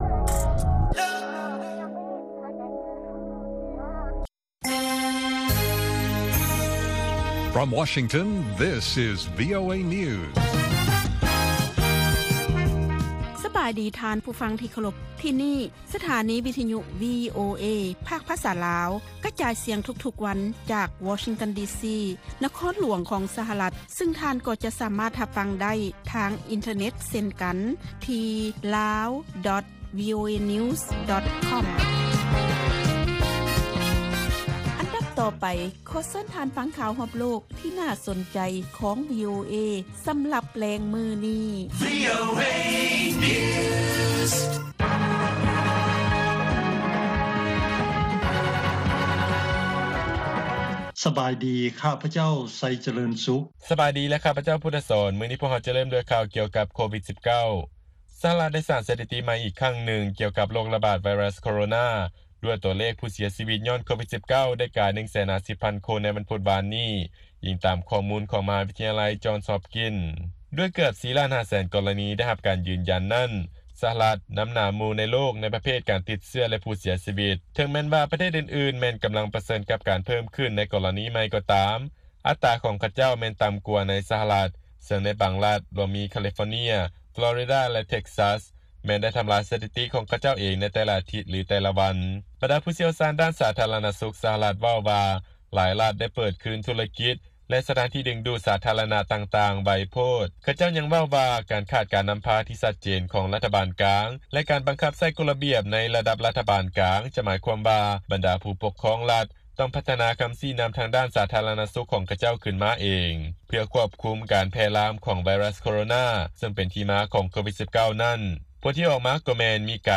ລາຍການກະຈາຍສຽງຂອງວີໂອເອ ລາວ
ວີໂອເອພາກພາສາລາວ ກະຈາຍສຽງທຸກໆວັນ.